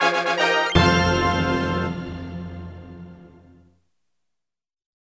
you'll hear this little tune.